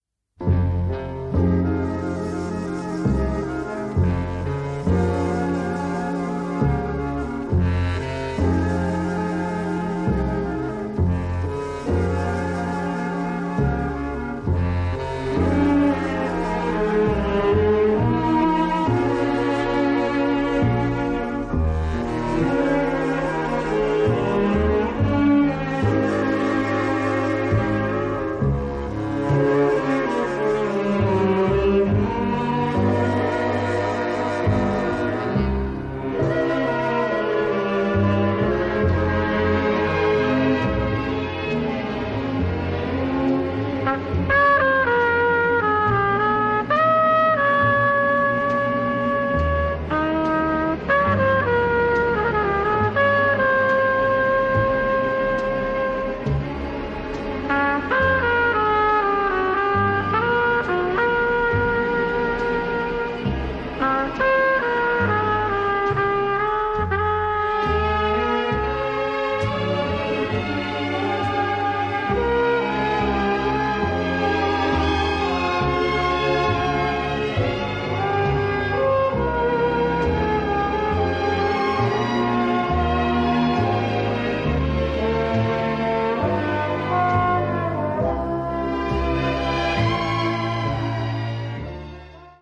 the melodies are stunningly beautiful.